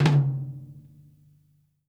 FLAM      -R.wav